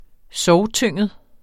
Udtale [ -ˌtøŋˀəð ]